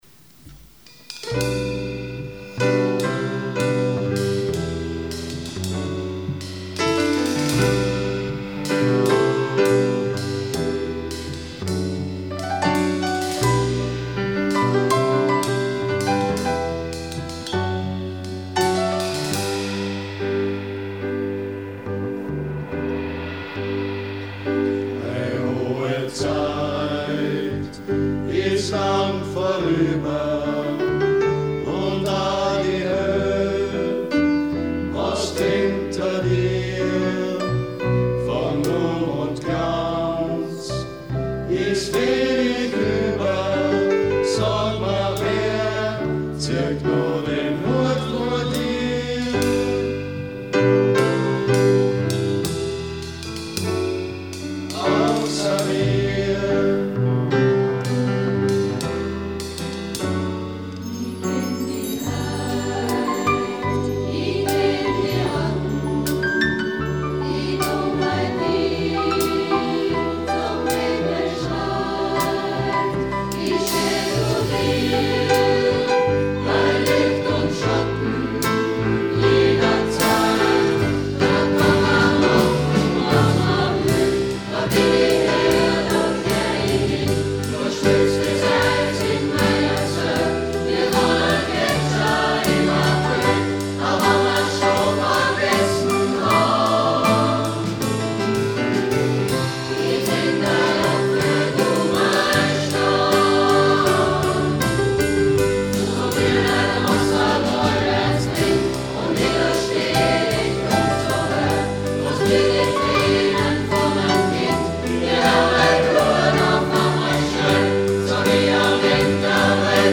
Am 18. Oktober 2024 fand um 19:30 im Festsaal der Arbeiterkammer das große HERBSTKONZERT statt.